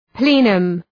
Shkrimi fonetik {‘pli:nəm}